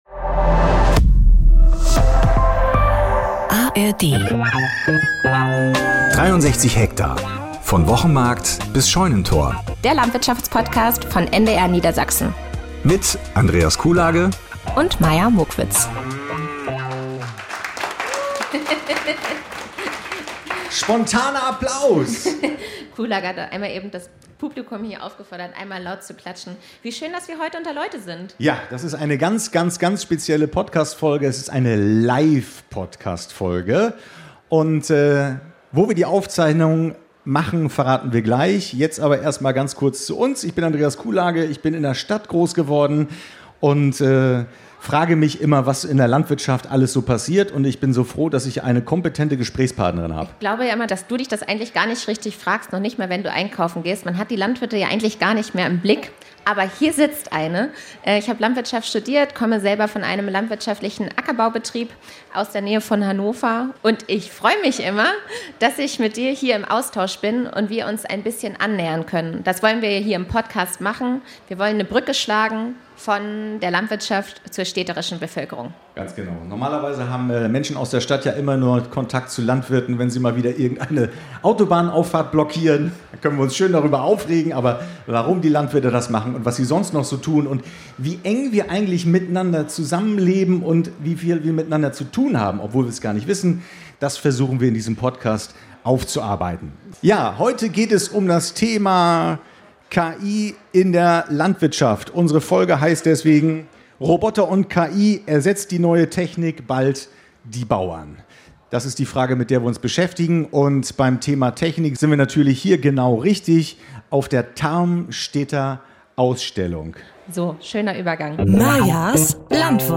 Live-Podcast von der Tarmstedter Ausstellung (#29) ~ 63 Hektar - der Landwirtschafts-Podcast von NDR Niedersachsen Podcast